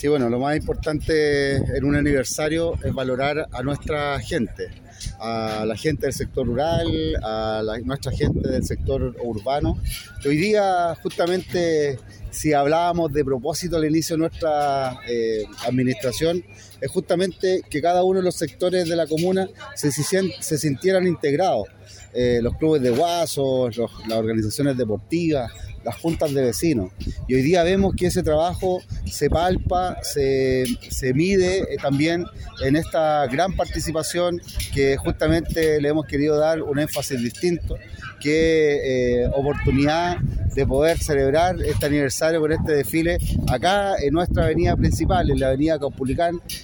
Frente al edificio consistorial se desarrolló el acto cívico y desfile del aniversario de Los Vilos.
Posteriormente y en su intervención, el alcalde Christian Gross expresó un mensaje de unión recalcando que “ lo más importante en un aniversario es valorar a nuestra gente, a la del sector rural y sector urbano.